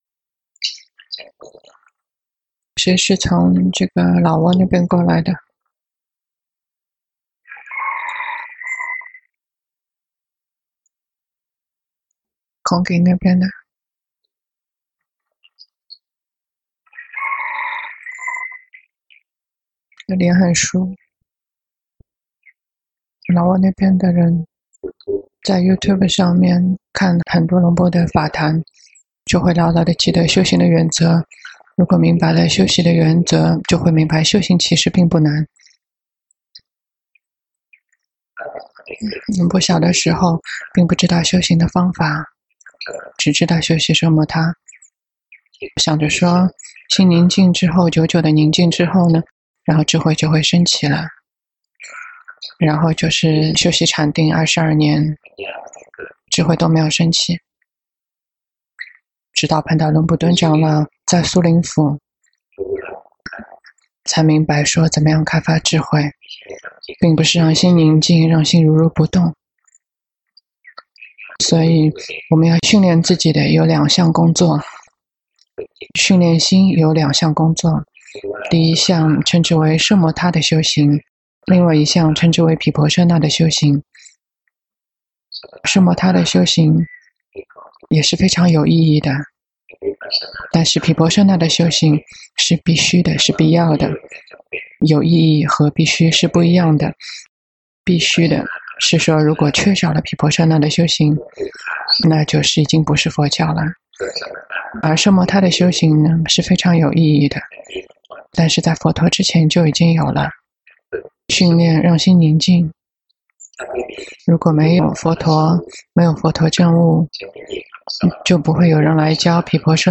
長篇法談｜訓練心的兩項工作
同聲翻譯